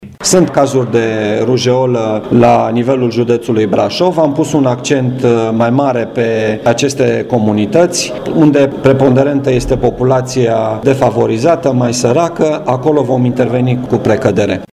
Prefectul județului Brașov, Marian Rasaliu, a concluzionat aspectele desprinse după discuțiile cu primarii: